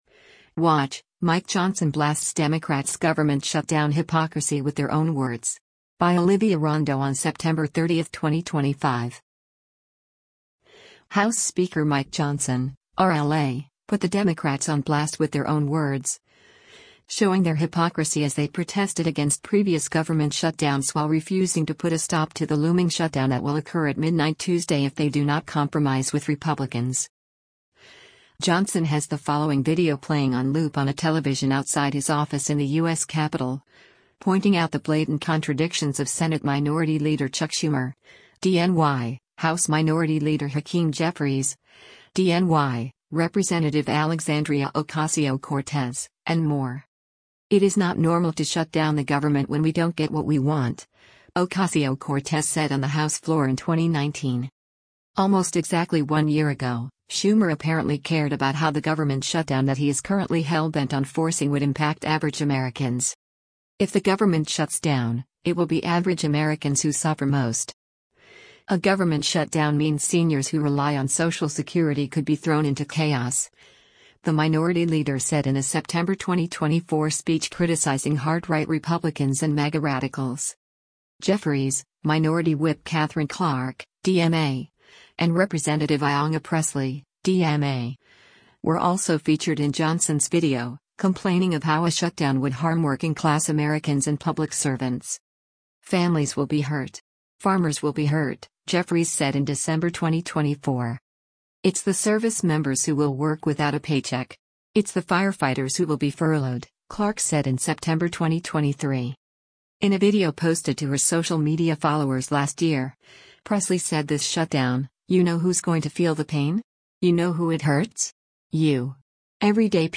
“It is not normal to shut down the government when we don’t get what we want,” Ocasio-Cortez said on the House floor in 2019.
Jeffries, Minority Whip Katherine Clark (D-MA), and Rep. Ayanna Pressley (D-MA) were also featured in Johnson’s video, complaining of how a shutdown would harm working-class Americans and public servants.